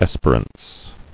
[es·per·ance]